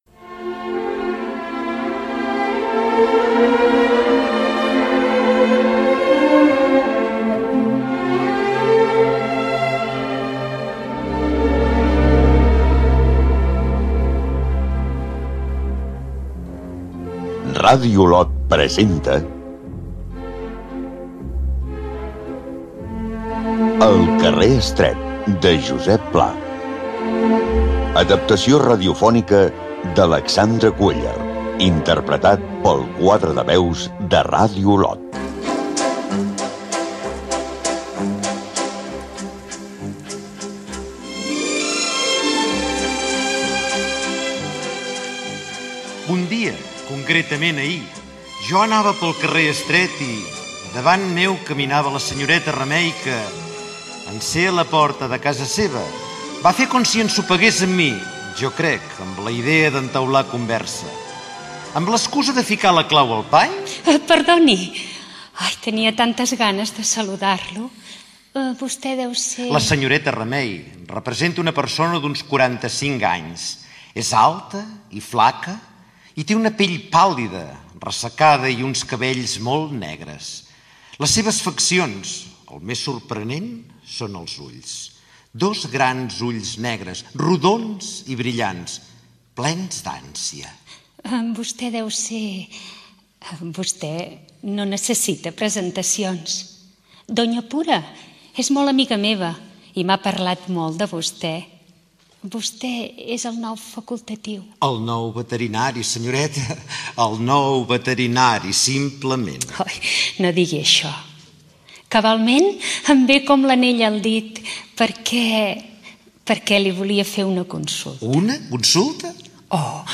Versió radiofònica de l'obra "El carrer estret", de Josep Pla.
Careta del programa i escena entre la protagonista i el veterinari.
Comiat del programa
Ficció